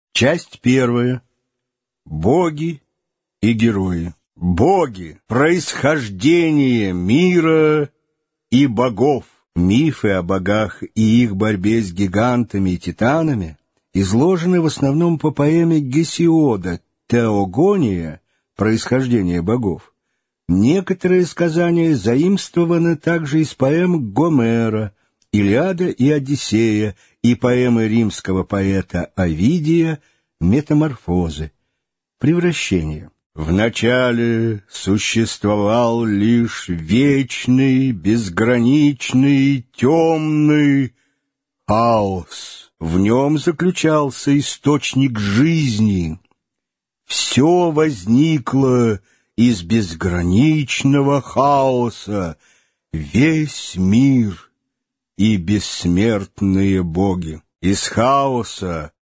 Аудиокнига Легенды и мифы Древней Греции | Библиотека аудиокниг